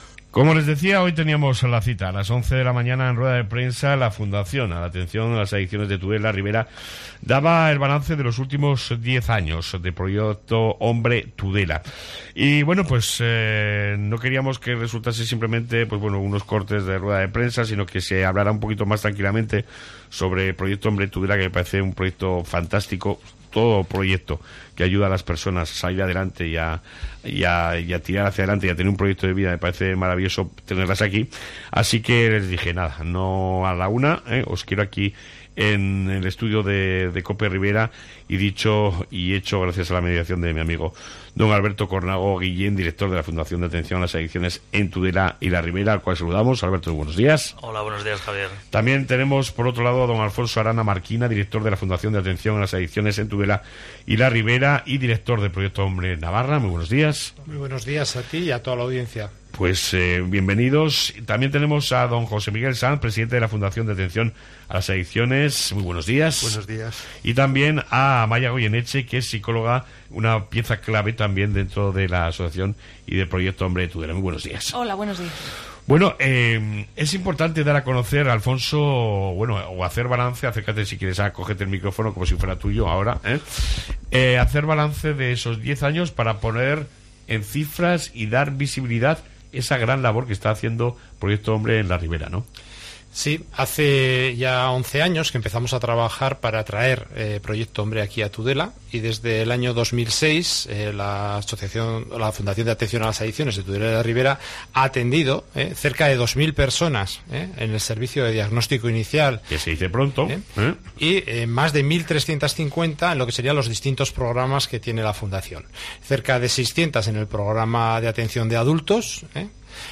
Entrevista con Proyecto Hombre Ribera en su balance 2007-2017